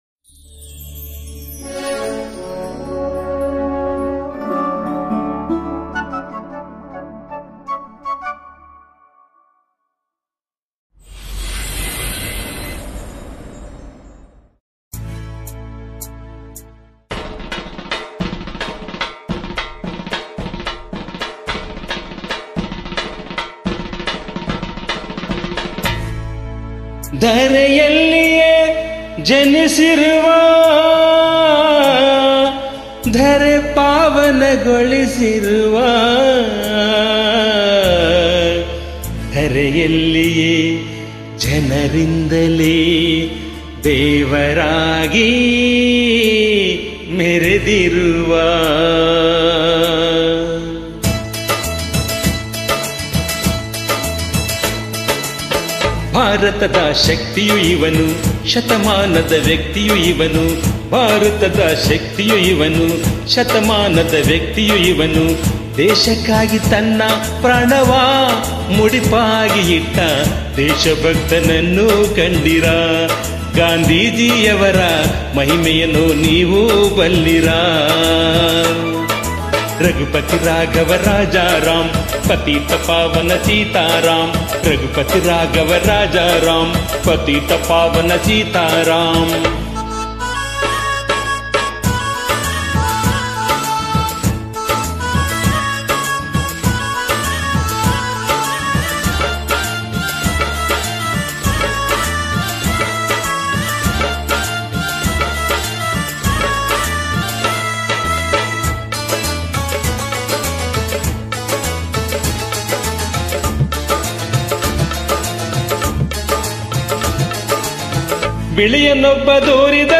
ಸಾಹಿತ್ಯ ಹಾಗೂ ಗಾಯನ: